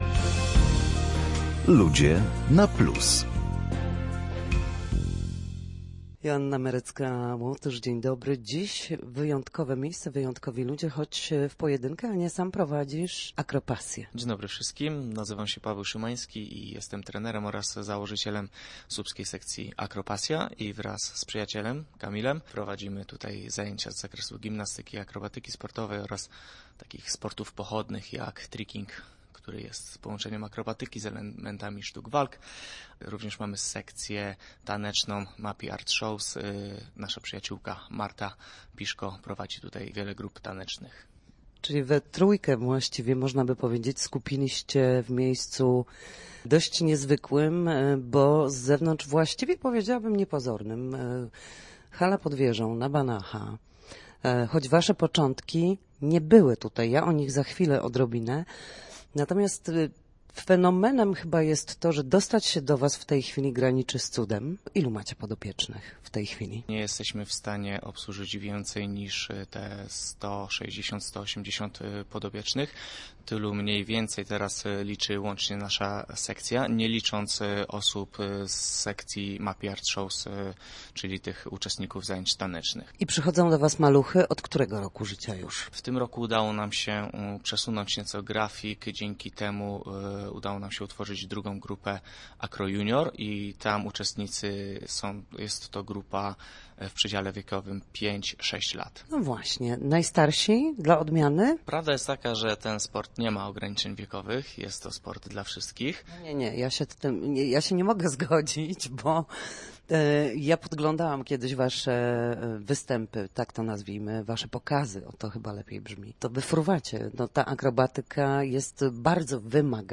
Na naszej antenie mówił o początkach i o tym, jak marzenia stają się rzeczywistością.